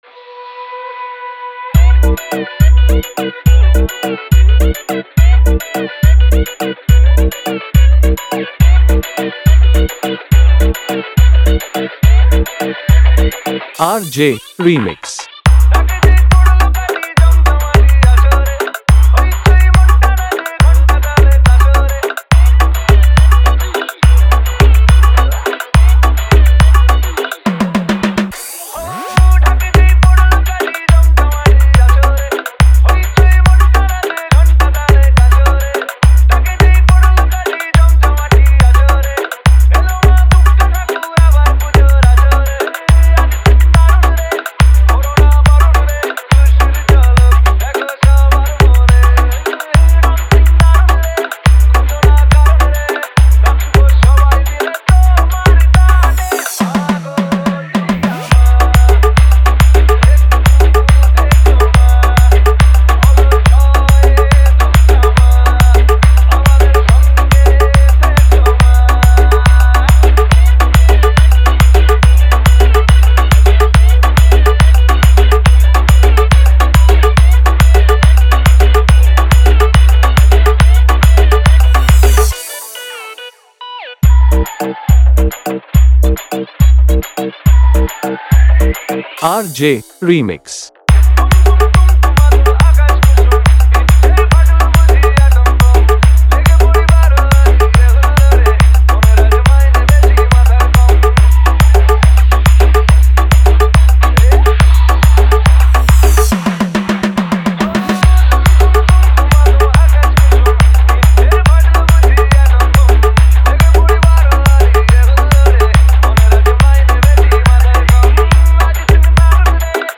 দুর্গ উৎসব 2024 স্পেশাল হামবিং ভক্তি বাংলা গান